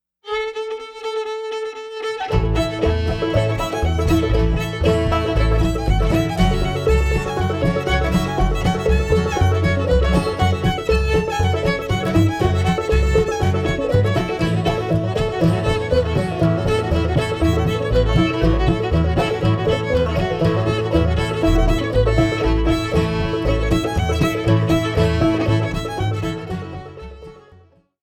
Old Time Music of SW Pennsylvania
fiddle
banjo, fife, accordion
guitar
upright bass Between 1928 and 1963